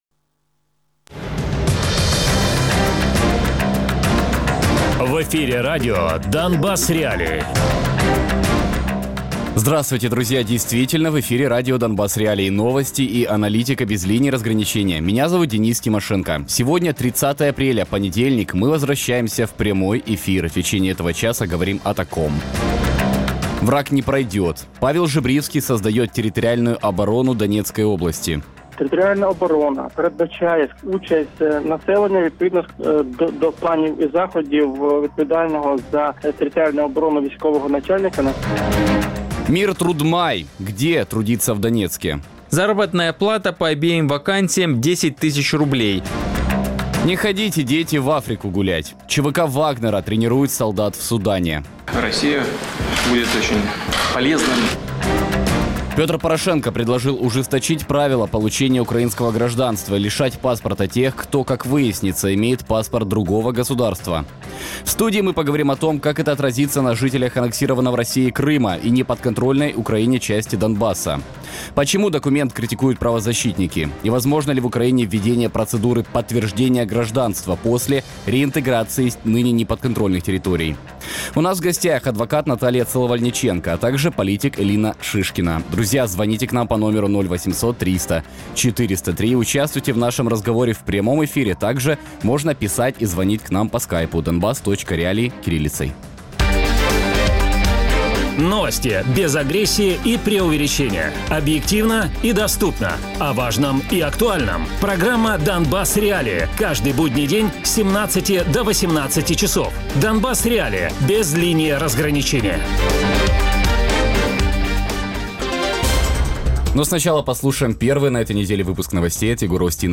Радіопрограма